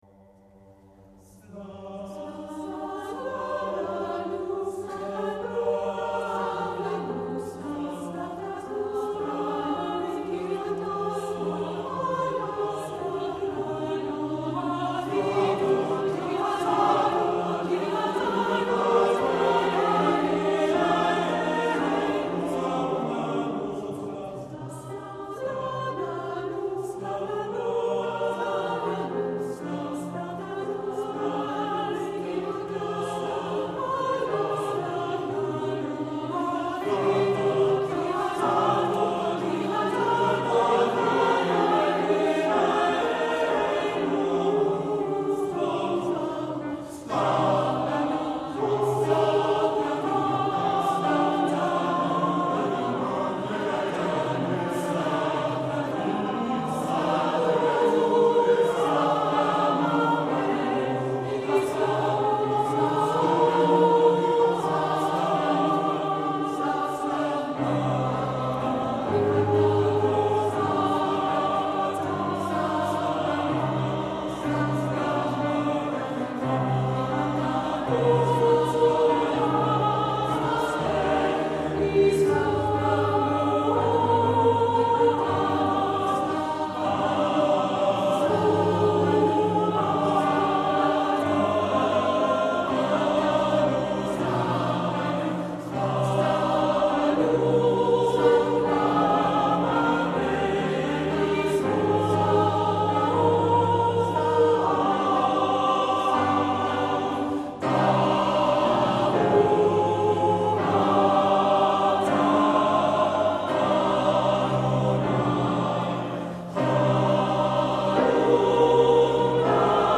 Nur Klavierbegleitung